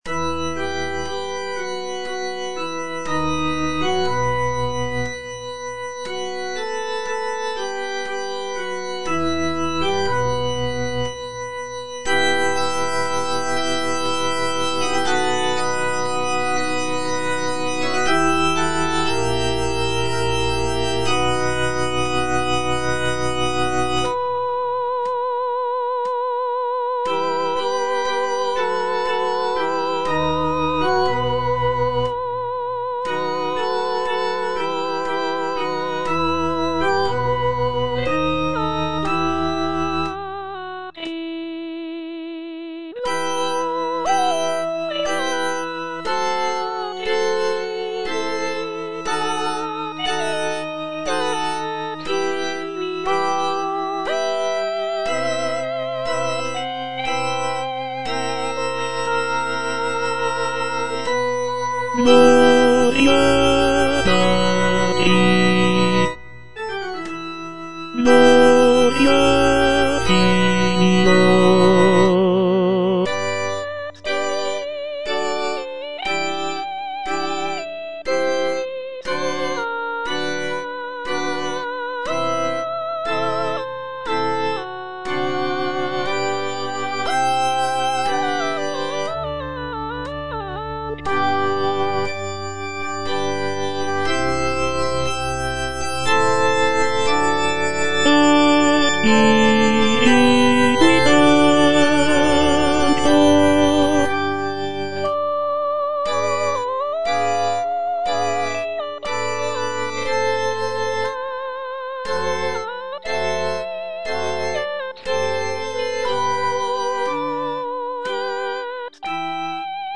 B. GALUPPI - MAGNIFICAT Gloria - Tenor (Voice with metronome) Ads stop: auto-stop
"Magnificat" by Baldassare Galuppi is a sacred choral work based on the biblical text of the Virgin Mary's song of praise from the Gospel of Luke.
The work features intricate vocal lines, rich harmonies, and dynamic contrasts, creating a powerful and moving musical experience for both performers and listeners.